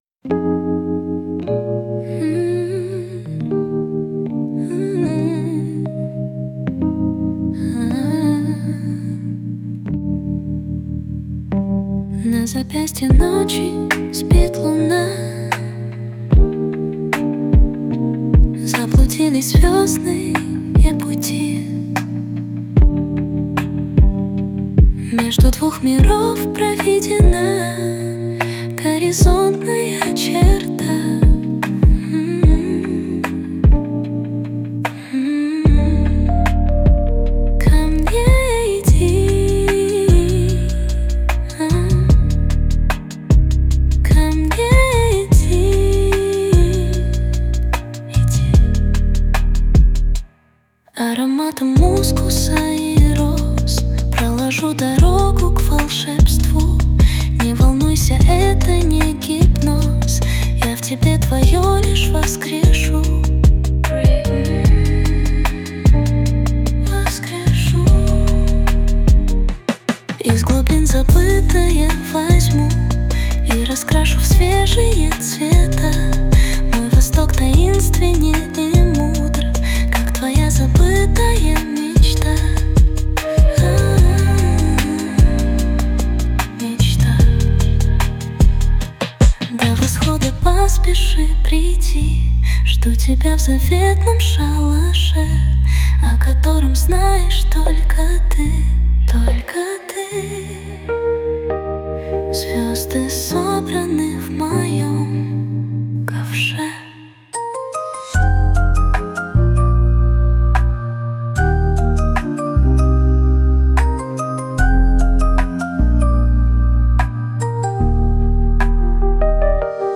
mp3,3372k] Авторская песня